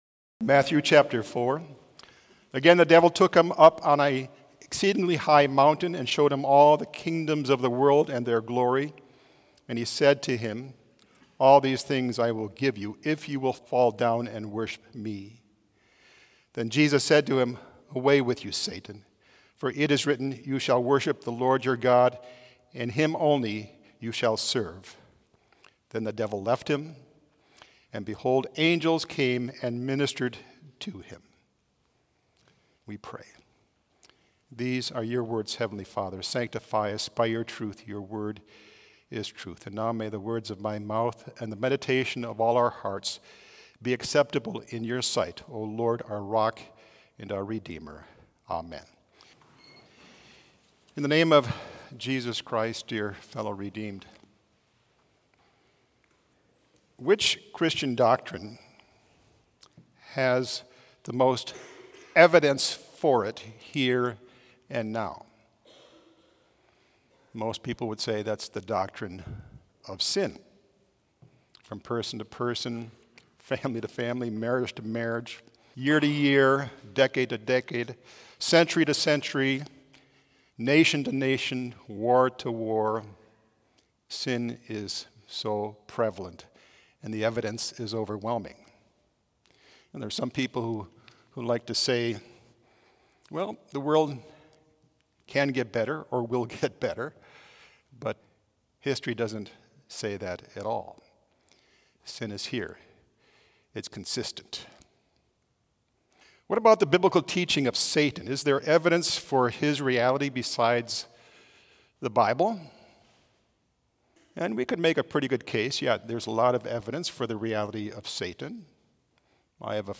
Complete service audio for Chapel - Friday, September 27, 2024